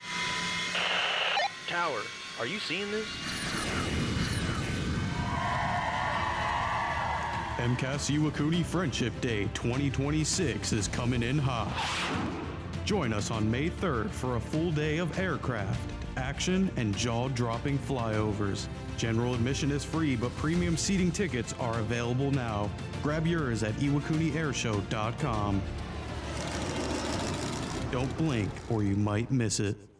A 30-second radio spot production